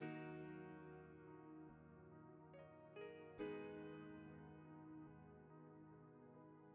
[Loop] I Got More Keys.wav